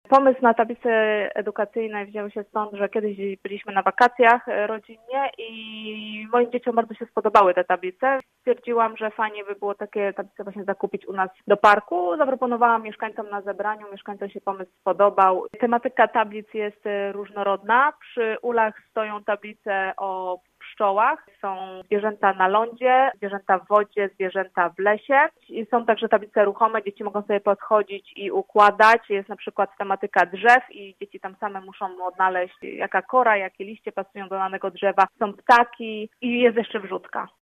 Jak mówi Aneta Walczak, sołtys Kiełpina, tablice zostały zamontowane z myślą o dzieciach, które spędzają czas w parku: